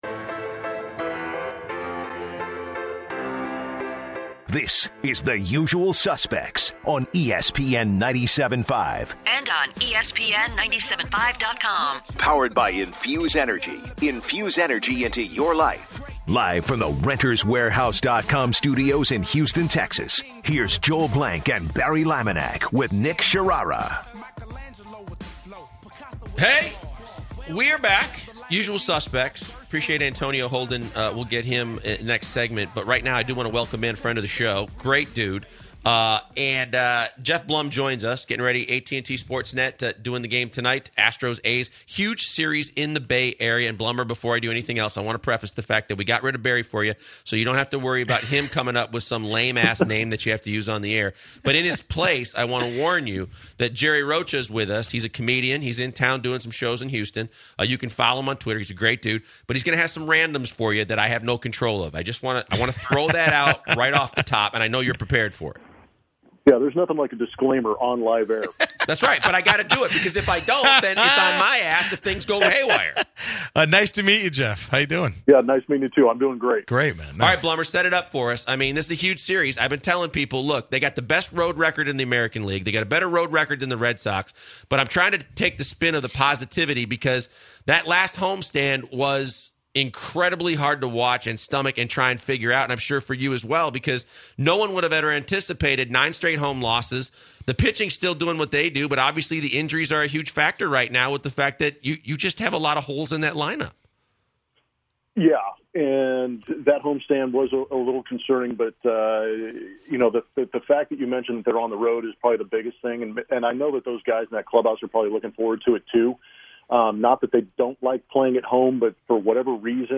08/17/2018 The Usual Suspects- Geoff Blum Interview